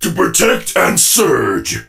surge_lead_vo_02.ogg